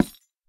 Minecraft Version Minecraft Version latest Latest Release | Latest Snapshot latest / assets / minecraft / sounds / block / hanging_sign / break2.ogg Compare With Compare With Latest Release | Latest Snapshot
break2.ogg